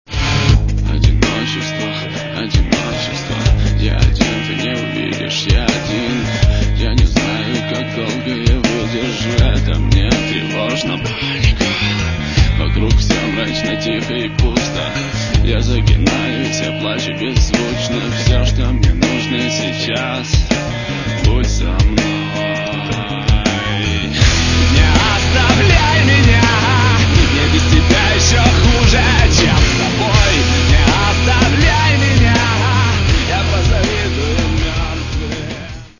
Каталог -> Рок та альтернатива -> Енергійний рок